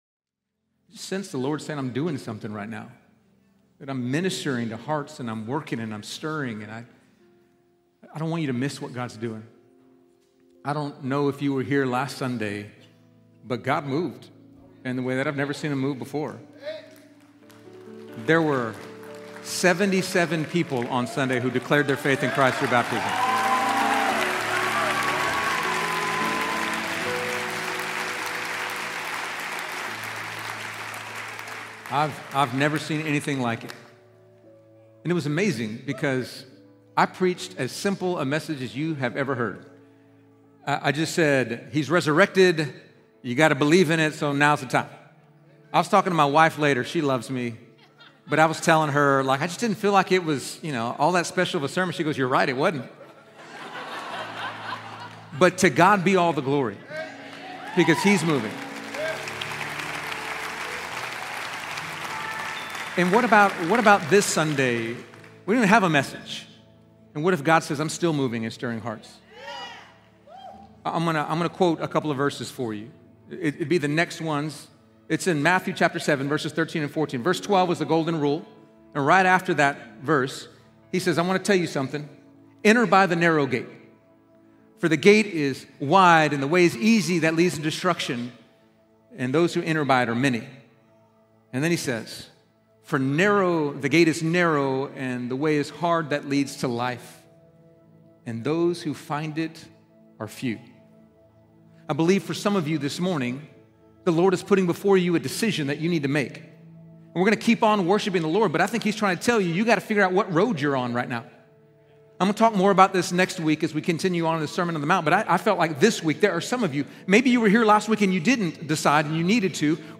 Artwork for podcast Fielder Church Sermons